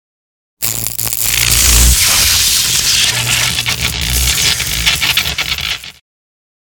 Разряд электричества 14 дек. 2023 г.
Мощный разряд электричества